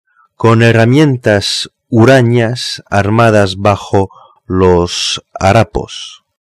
POUR ENTENDRE LA PRONONCIATION EN ESPAGNOL